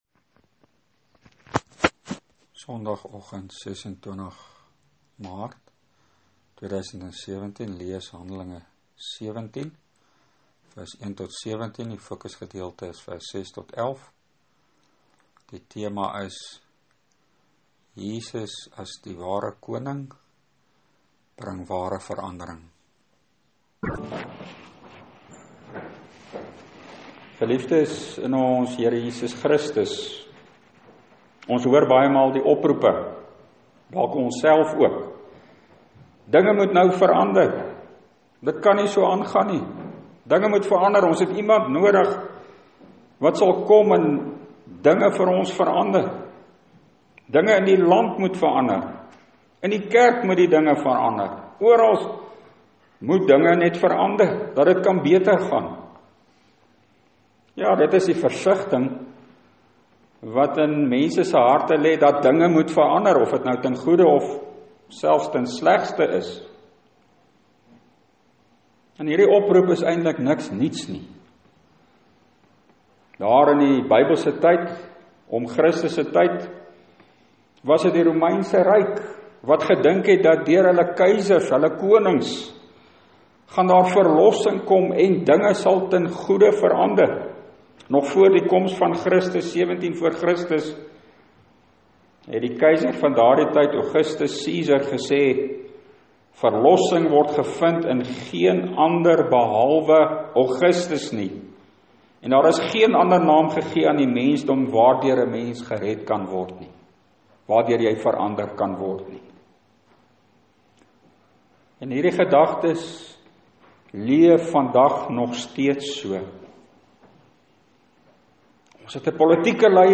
Preek: Hand.17:6-17 Koning Jesus bring ware verandering